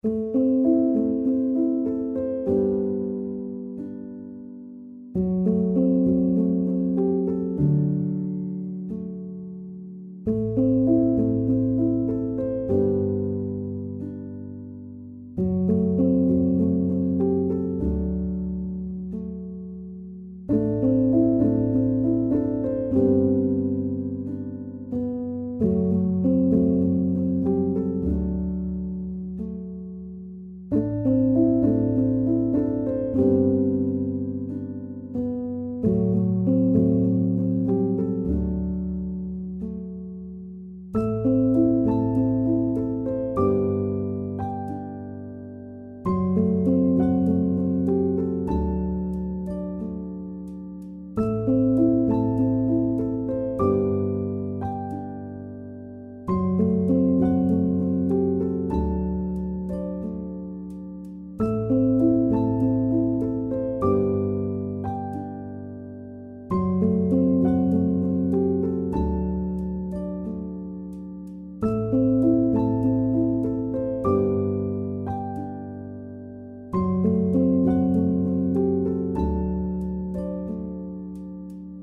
Genre: Mysterious